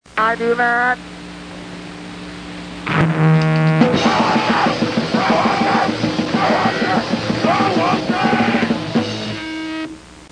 Lehigh Valley's grind/mayhem/wrestling-themed hardcore band
hardcore See all items with this value